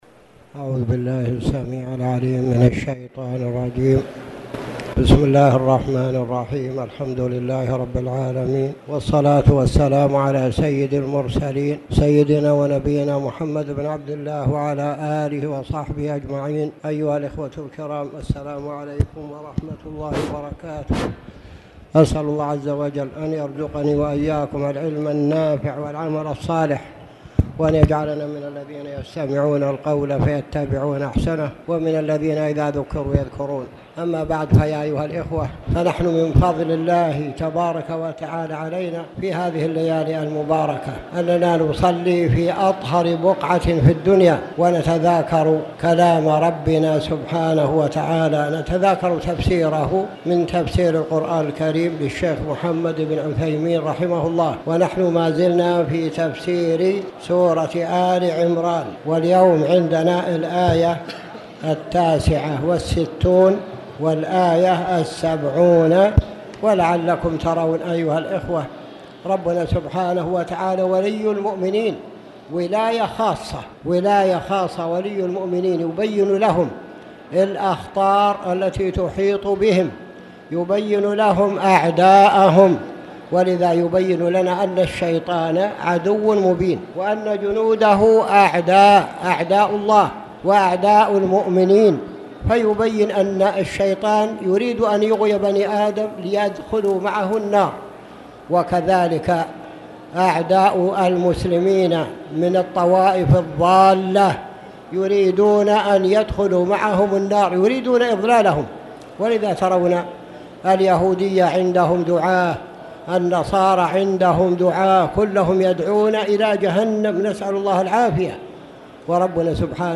تاريخ النشر ١٠ رمضان ١٤٣٨ هـ المكان: المسجد الحرام الشيخ